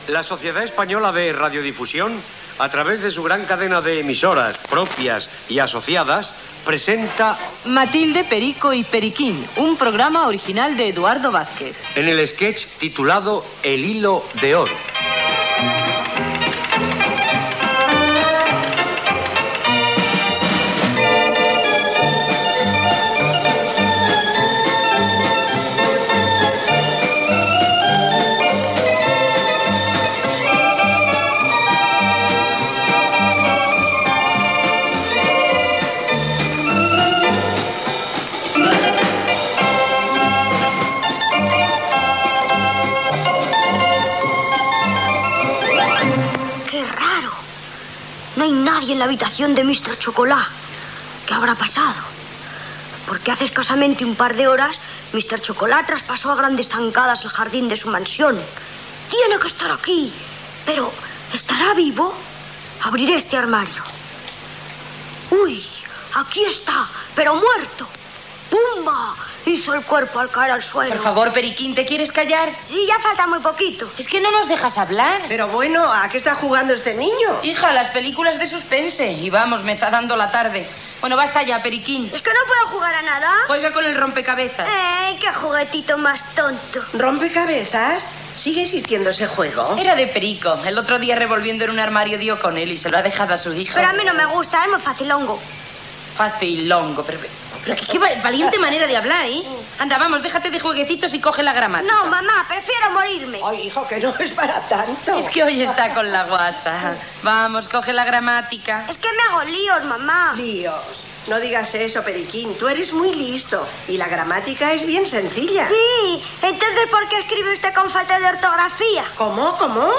Careta del programa i fragment de l'sketch "El hilo de oro"
Ficció